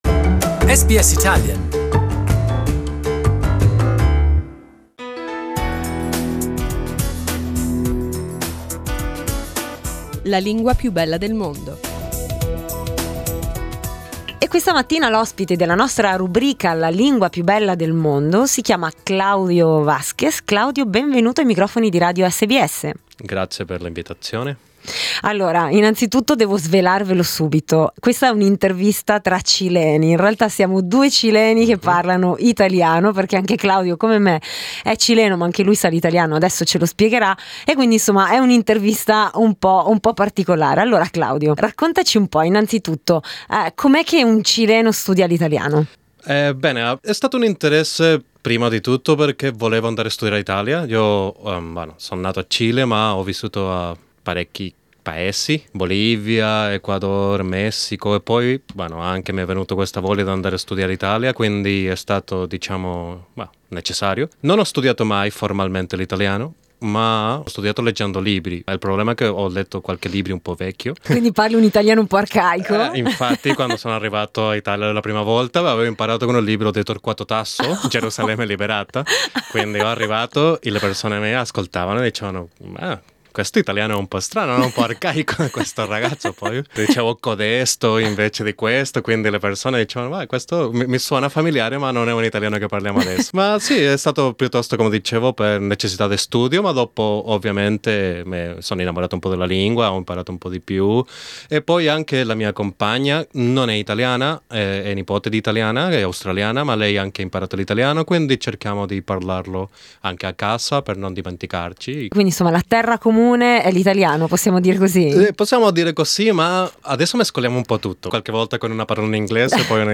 Being Chilean he finds that learning Italian as a Spanish speaker has both its advantages and challenges. His advice for whoever is learning Italian?